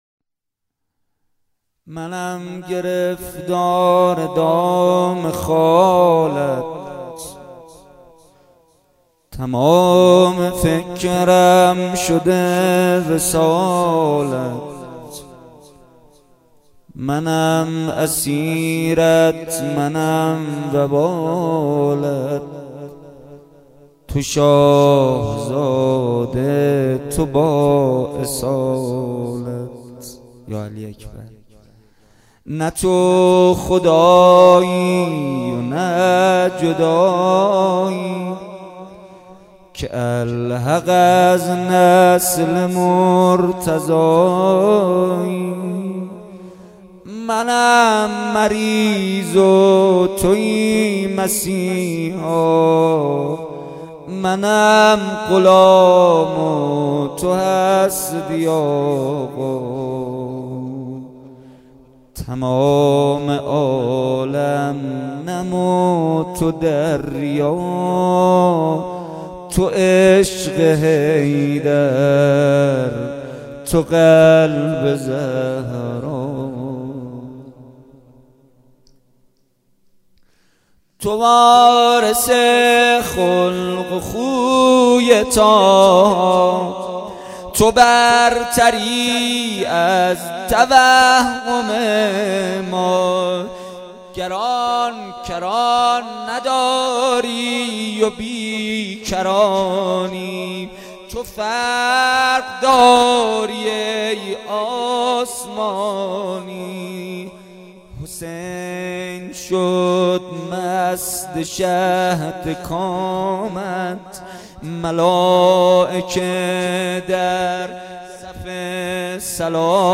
بخش اول - مناجات
بخش دوم - روضه